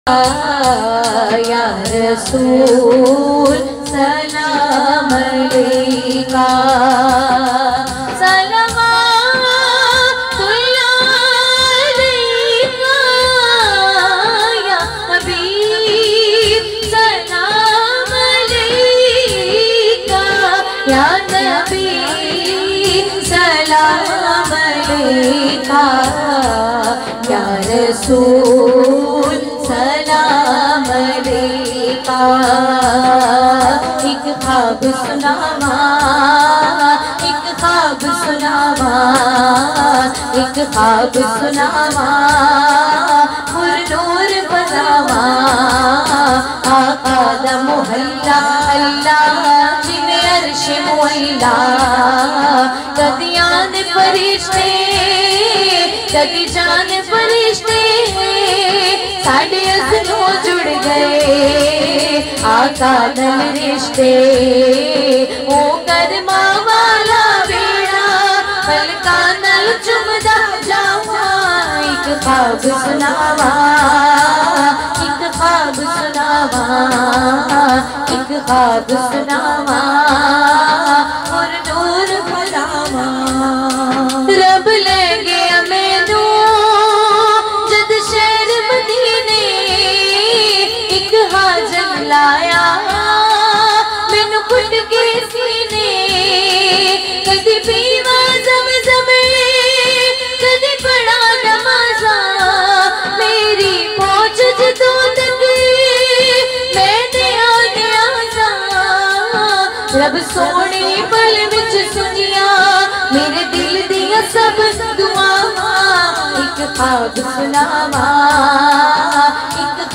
a Heart-Touching Voice